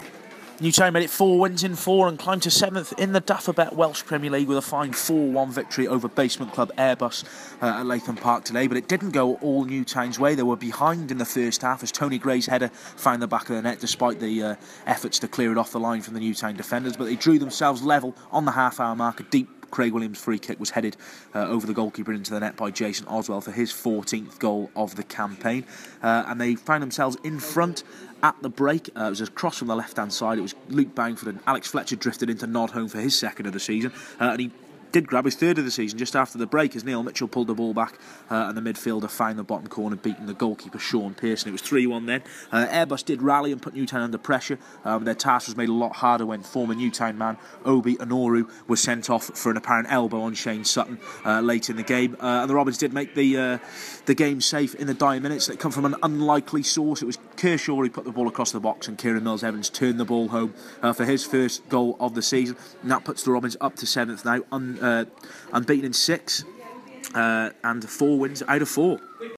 AUDIO REPORT - Robins 4-1 Airbus